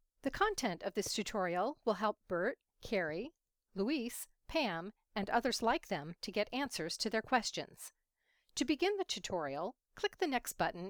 I’ve experienced this faint pop sound at intermittent intervals.
I’m using a Plantronics mic/headset with USB.
In the attached wav file, I hear it at least three times. It happens during a word in many cases, so I’m pretty sure it’s from something internal as I’ve been super careful with external noise.